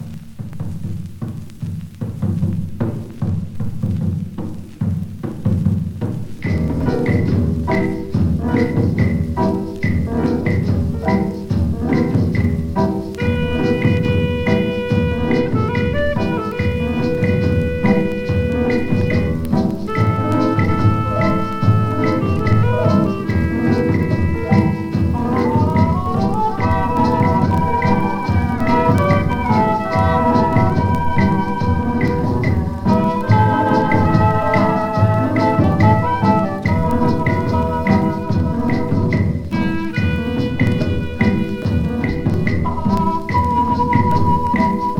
World, Latin, Rumba, Cubano　Germany　12inchレコード　33rpm　Mono